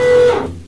assemblerStop.ogg